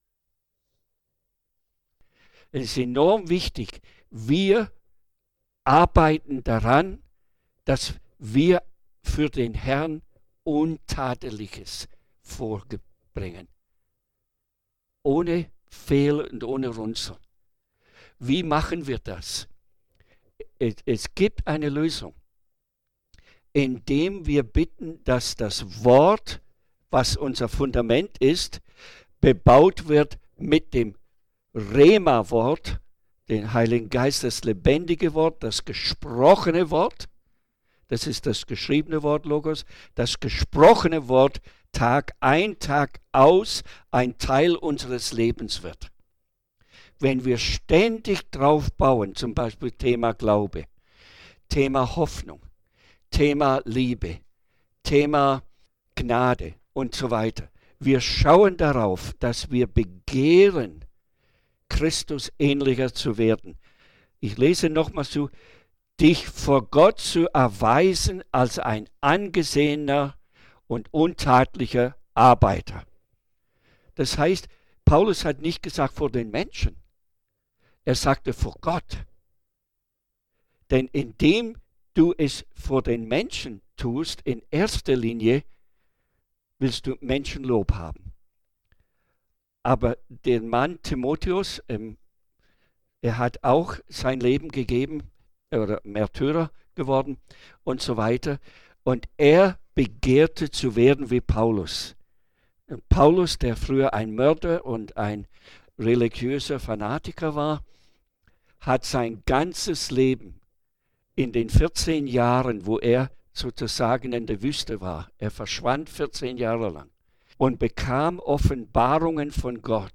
Referent